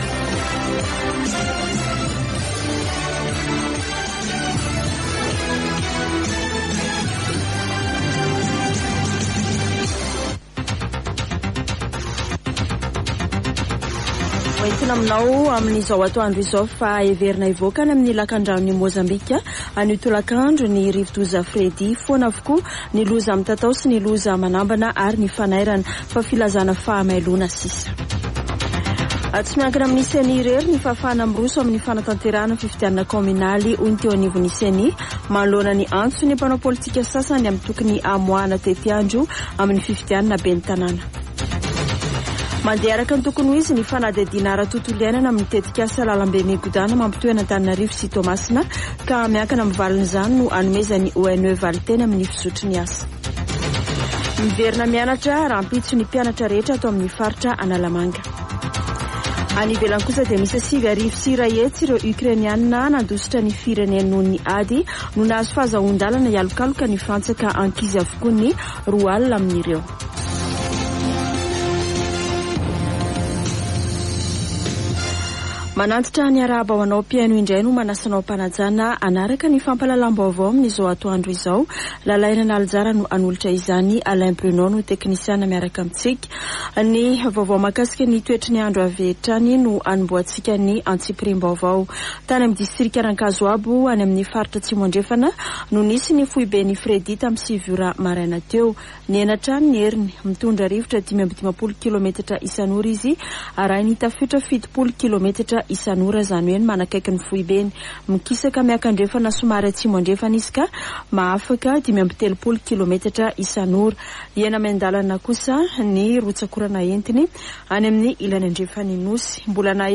[Vaovao antoandro] Alarobia 22 febroary 2023